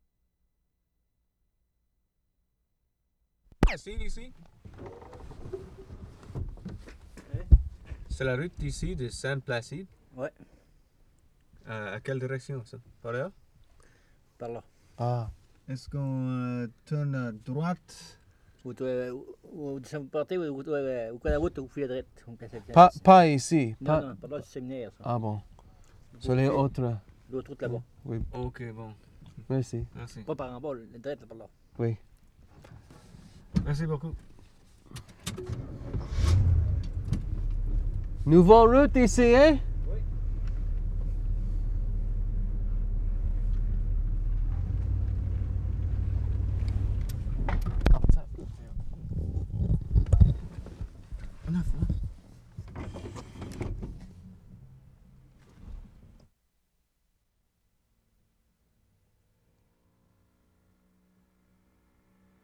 WORLD SOUNDSCAPE PROJECT TAPE LIBRARY
ST. LAURENT - NORTH SHORE, QUEBEC Oct. 26, 1973